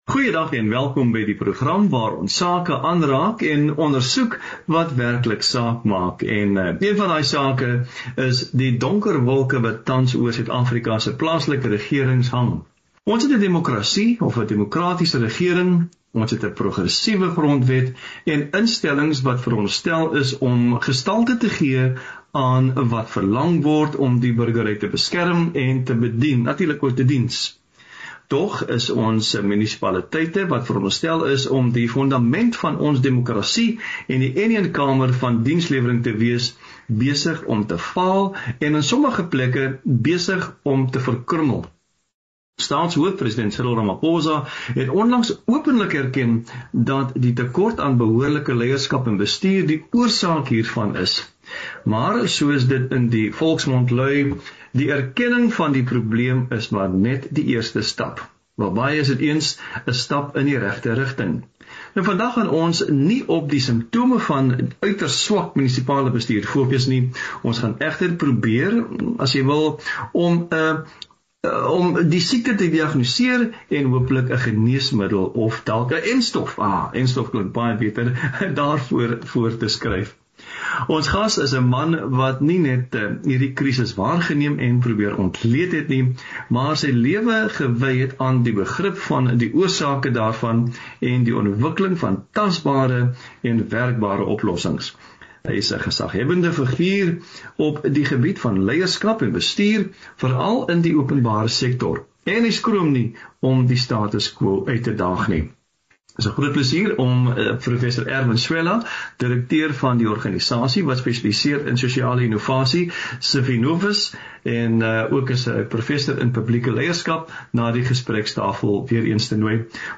Wat skort by ons munisipaliteite en is hierdie uitdagings oplosbaar onder die huidige burokratiese bestuur In gesprek